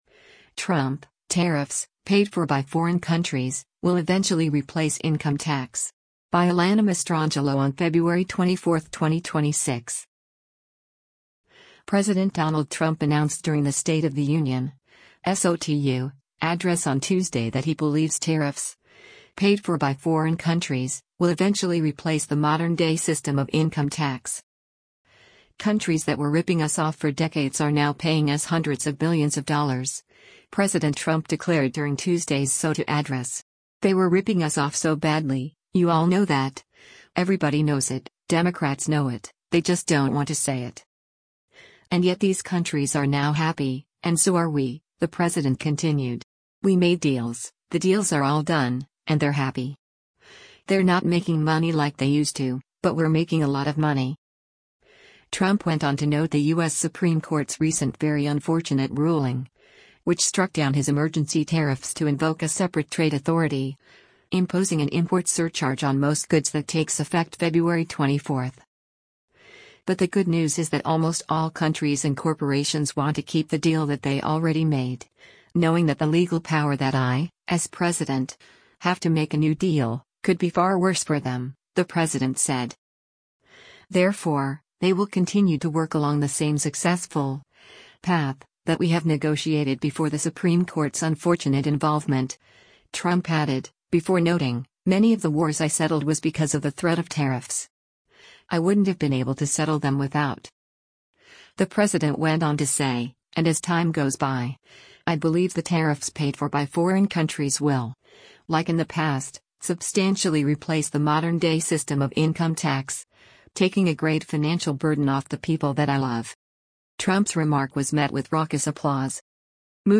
President Donald Trump announced during the State of the Union (SOTU) address on Tuesday that he believes tariffs, “paid for by foreign countries,” will eventually “replace the modern-day system of income tax.”
Trump’s remark was met with raucous applause.